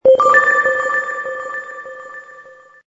ui_new_story_objective.wav